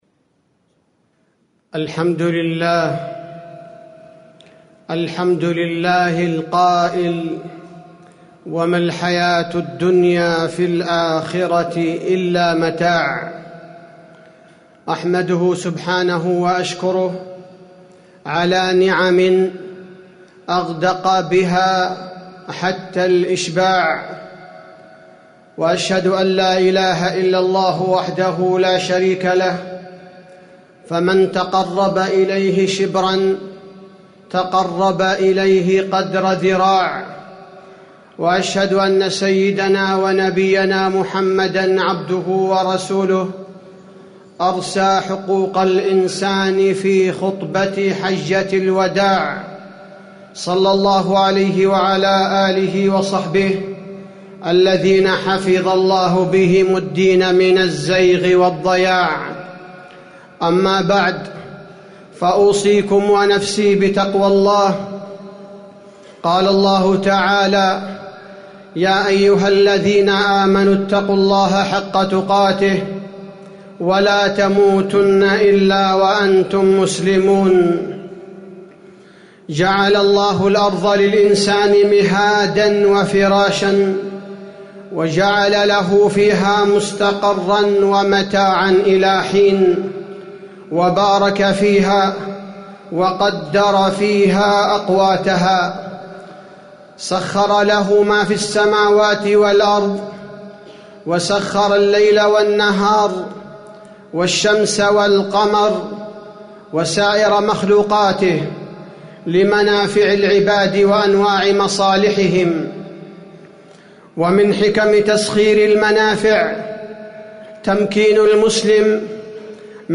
تاريخ النشر ٢٠ شوال ١٤٣٨ هـ المكان: المسجد النبوي الشيخ: فضيلة الشيخ عبدالباري الثبيتي فضيلة الشيخ عبدالباري الثبيتي الترويح المباح والمحرم